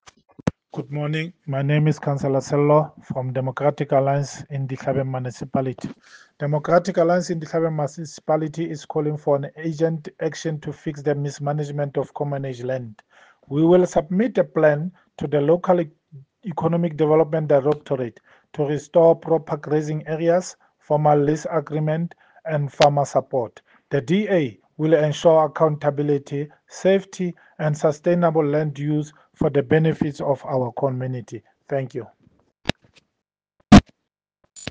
Sesotho soundbites by Cllr Sello Makoena.